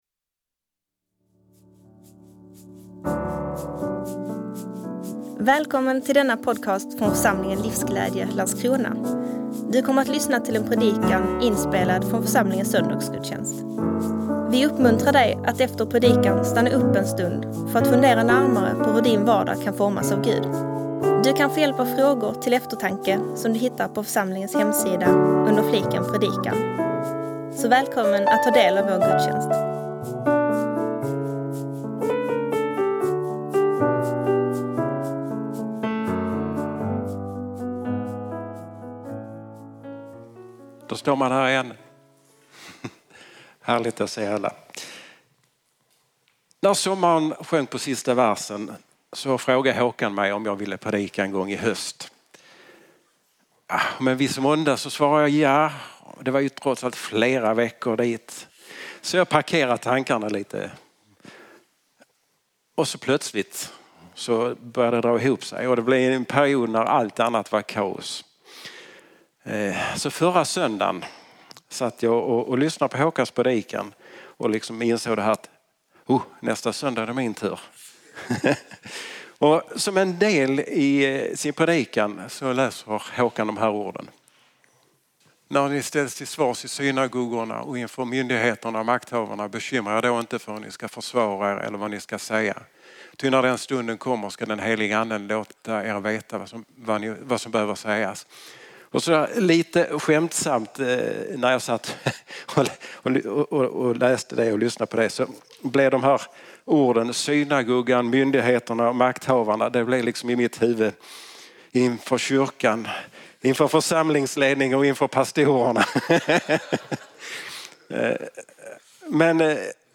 predikar om den helige Ande.